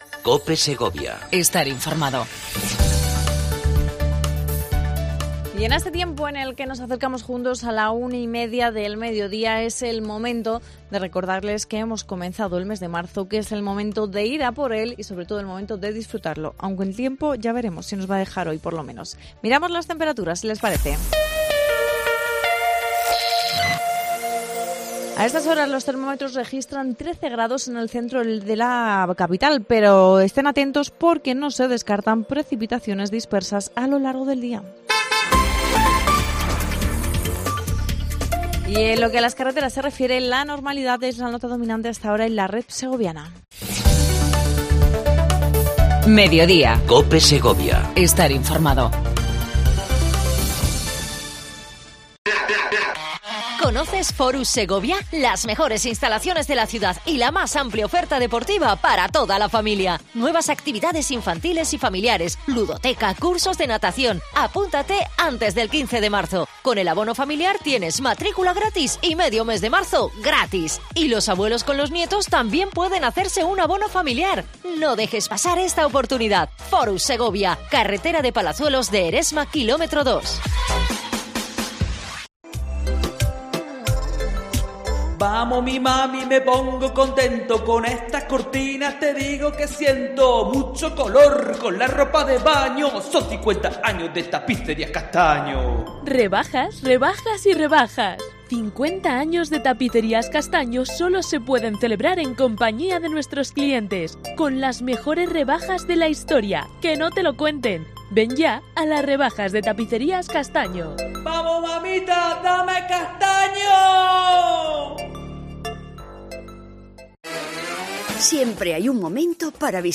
Entrevista a Miguel Ángel de Vicente, Vicepresidente de la Diputación y Diputado de Asuntos Sociales en Segovia.